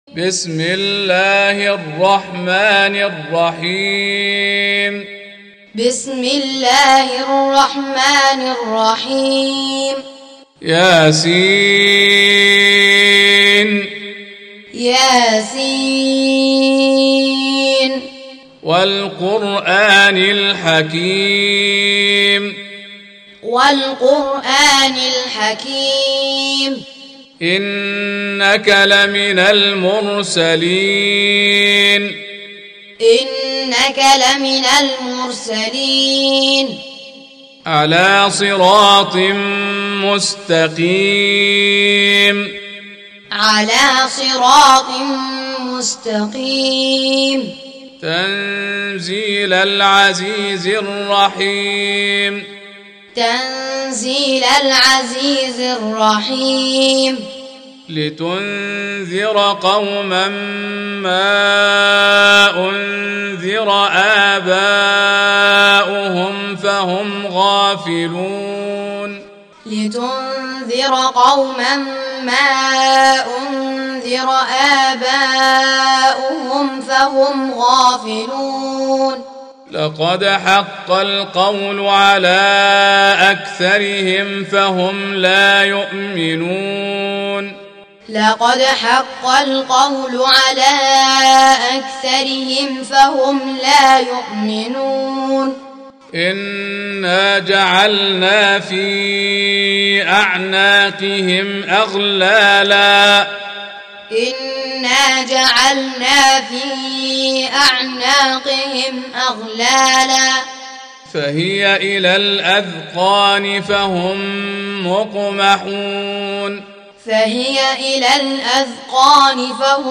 36. Surah Y�S�n. سورة يس Audio Quran Taaleem Tutorial Recitation Teaching Qur'an One to One